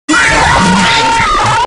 Five Nights at Freddy's 2 Full Scream Sound